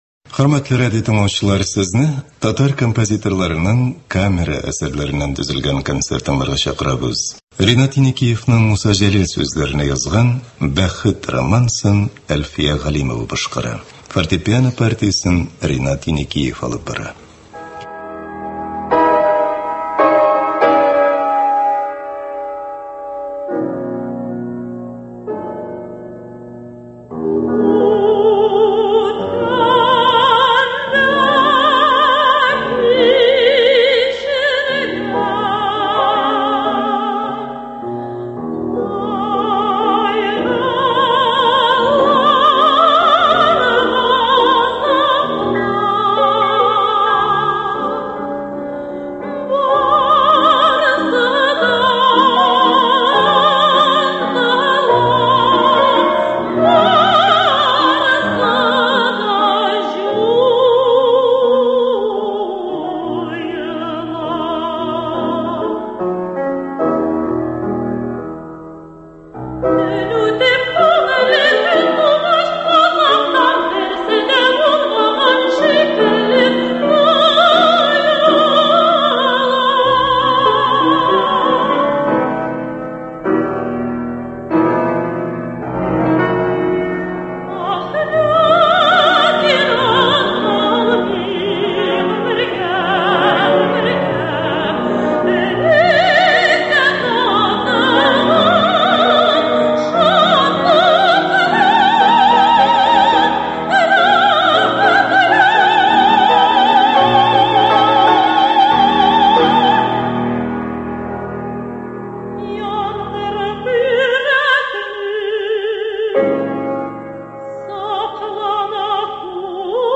Татар композиторларының камера әсәрләре.